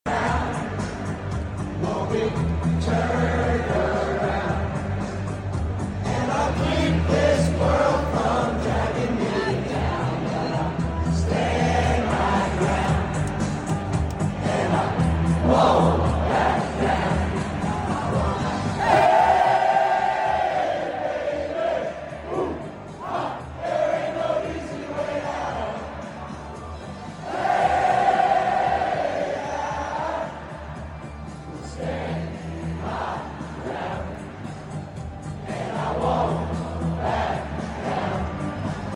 ever at UF vs LSU